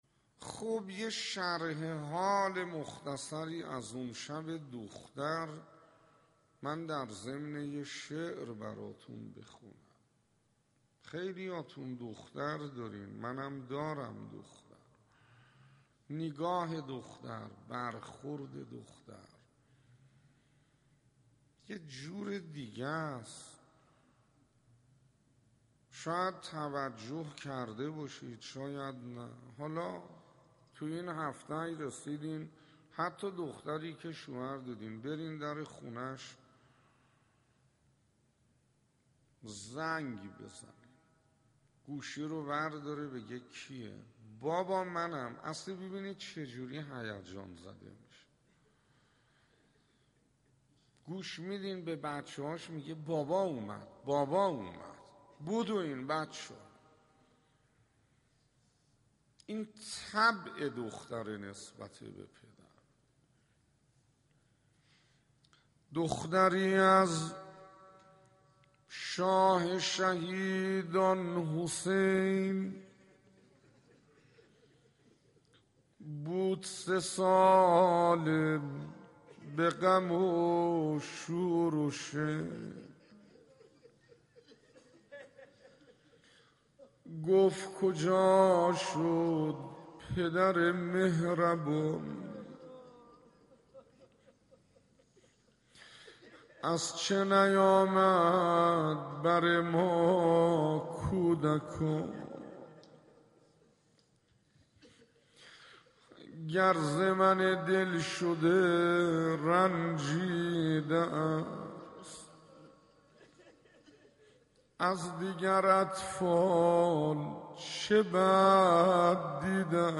روضه حضرت رقیه - 1
روضه حضرت رقیه - 1 خطیب: استاد حسين انصاريان مدت زمان: 00:06:11